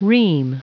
Prononciation du mot ream en anglais (fichier audio)
Prononciation du mot : ream